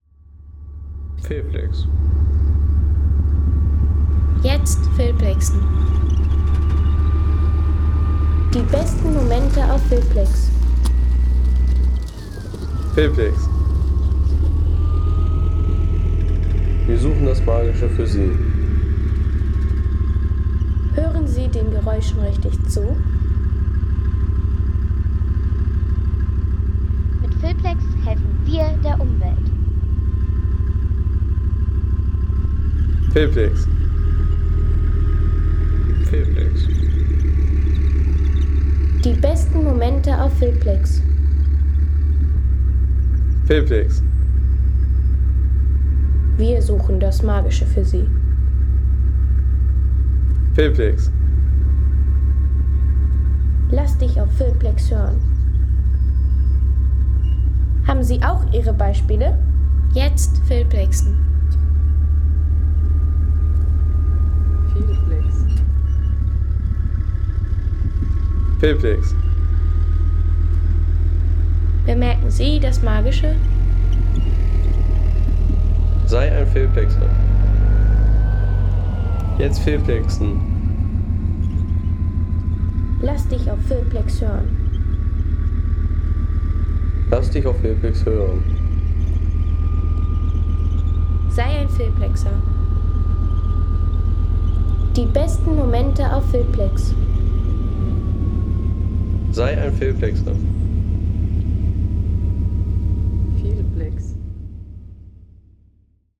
Carcrashing mit Leopard 1A3
Leopard 1A3: Stahlkoloss walzt ein Auto platt.